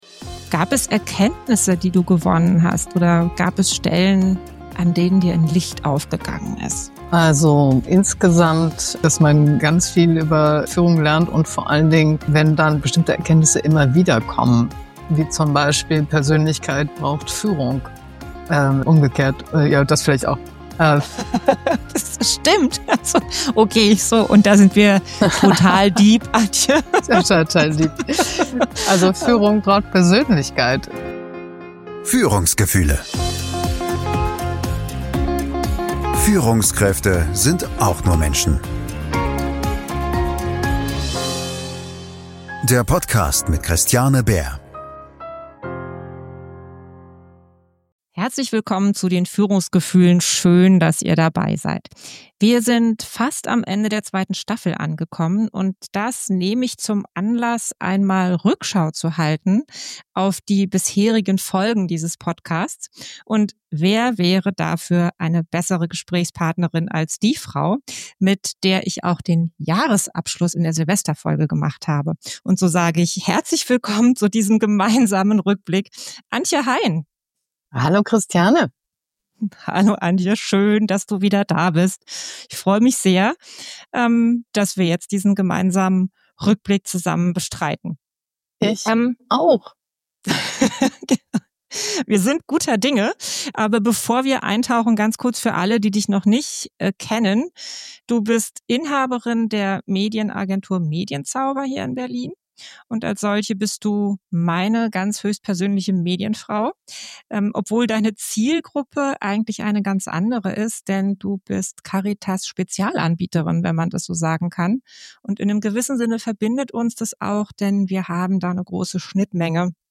Führung in Bewegung – Ein Staffelrückblick mit Herz und Haltung – Gespräch